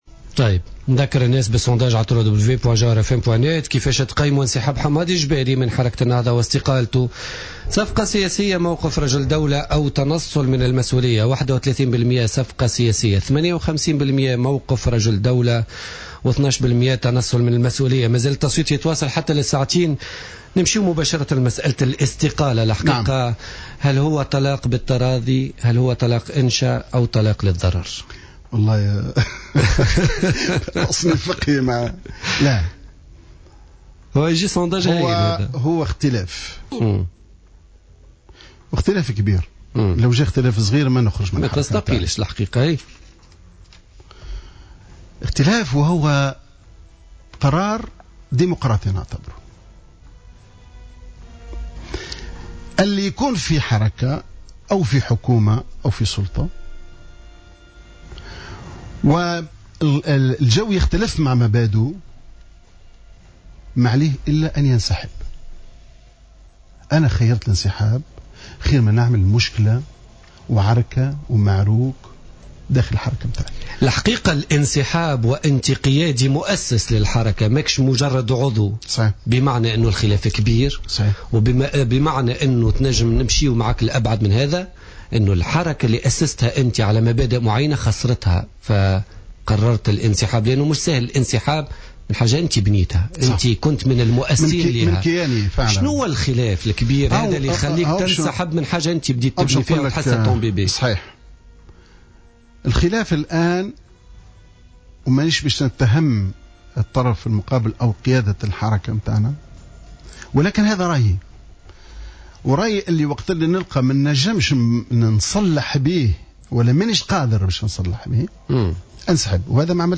وأكد الجبالي ضيف حصة بوليتيكا اليوم الاربعاء على جوهرة أف أم إن هذه المرحلة يجب أن تقوم على الحوار بين مختلف الأطياف مشددا على أهمية التحاور في هذا التوقيت حتى داخل حركة النهضة نفسها.